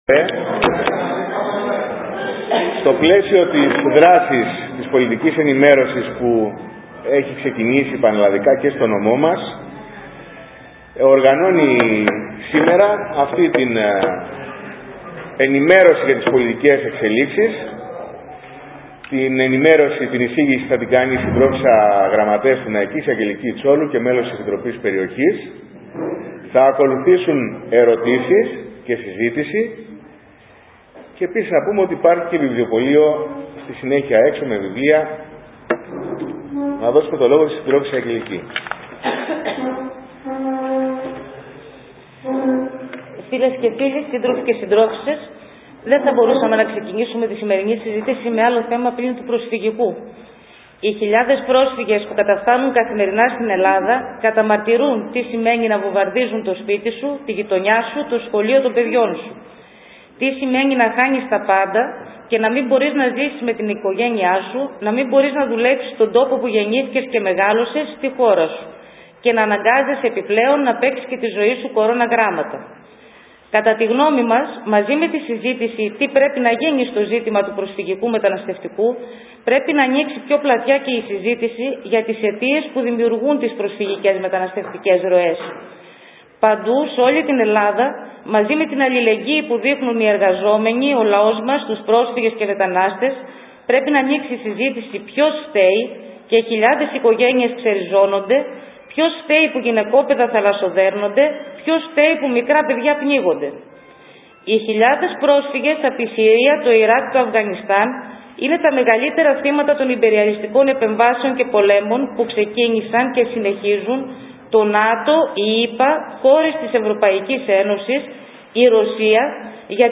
Ομιλία από το ΚΚΕ για τις πολιτικές εξελίξεις..
Θα έχετε την ευκαιρία να ακούσετε την ομιλία. Στο τέλος τέθηκαν ερωτήματα τα οποία απαντήθηκαν όλα από την ομιλήτρια.